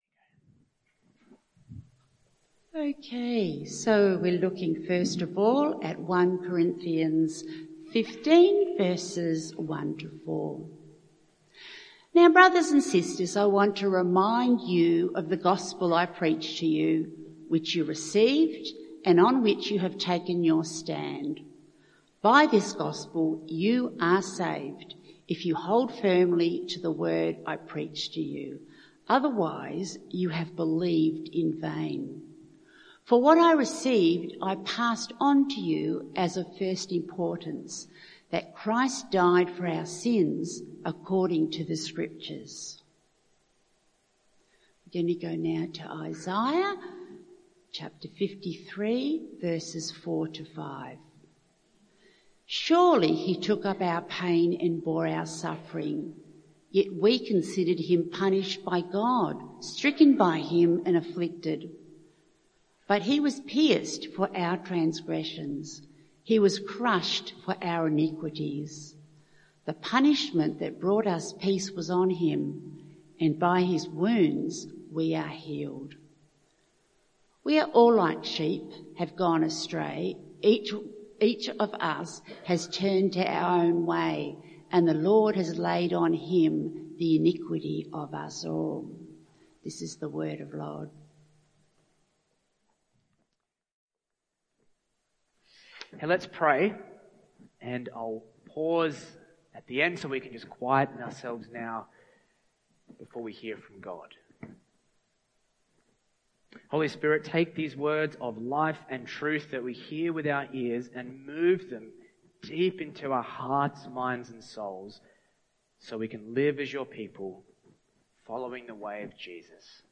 This talk launches a three-week series called Unhurried: how to live with God in a restless world by going back to the source of Christian life and transformation—the Cross of Jesus. Beginning with Paul’s words in 1 Corinthians, the message asks a confronting question: Is the Cross of first importance in your life, or has it become a symbol, logo, or add-on?